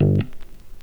Bass_Stab_02.wav